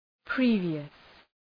Προφορά
{‘pri:vıəs}